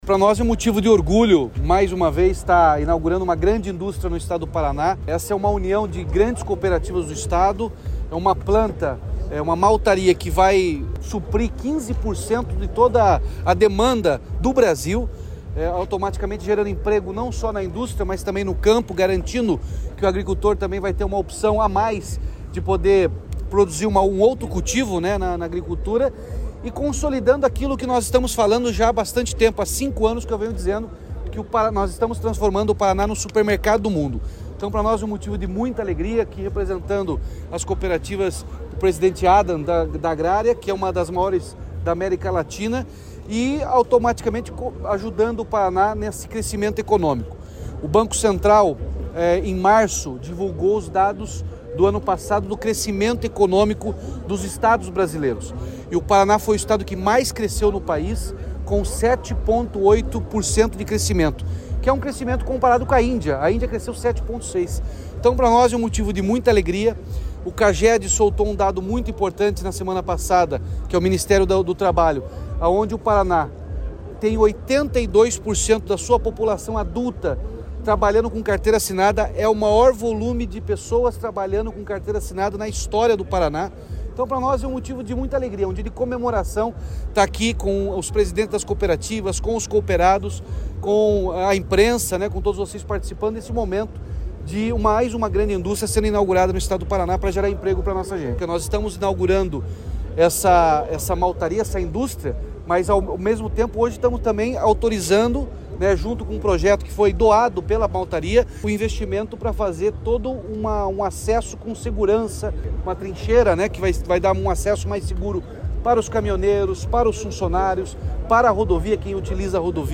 Sonora do governador Ratinho Junior sobre a inauguração da Maltaria Campos Gerais